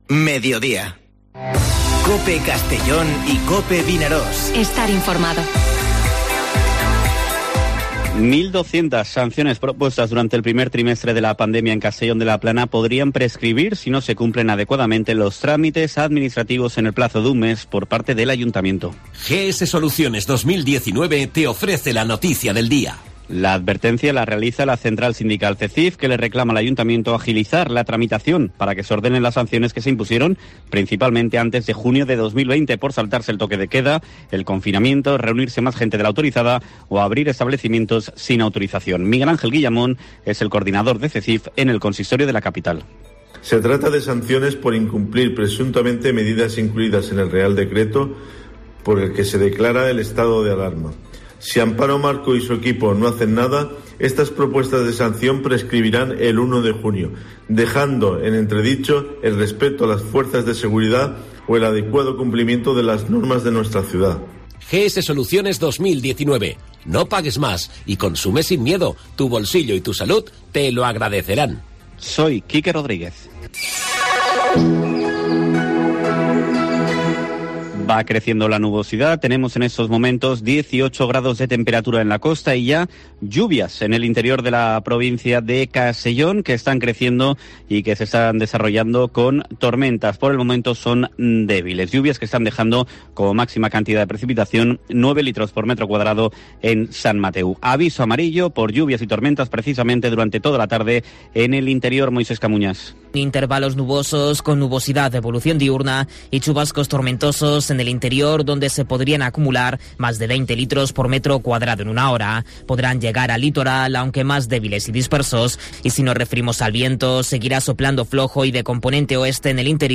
Informativo Mediodía COPE en la provincia de Castellón (28/04/2021)